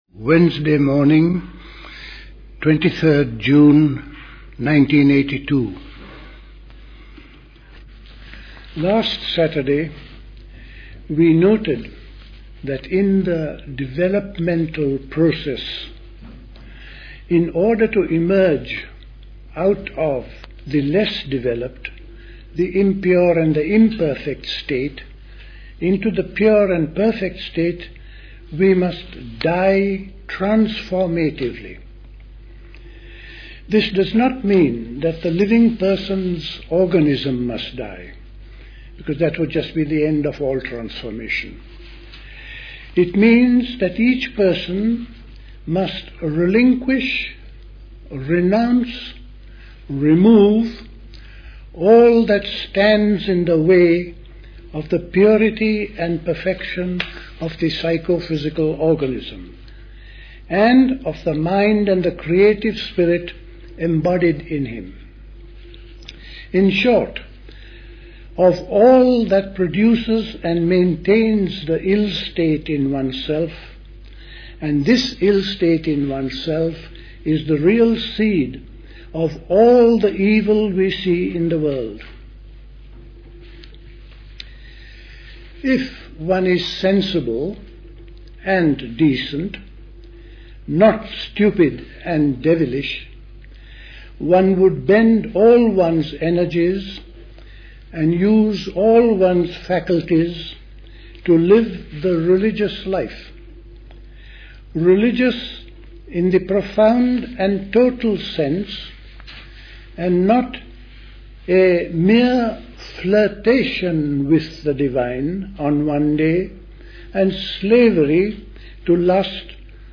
The Cenacle Summer School Talks